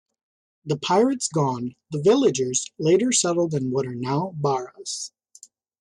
Pronounced as (IPA) /ˈpaɪɹəts/